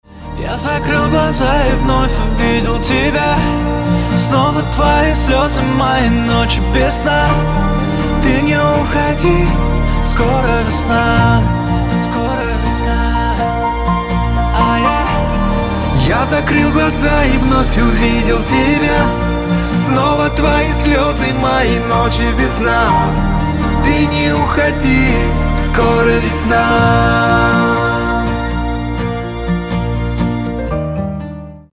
красивый мужской голос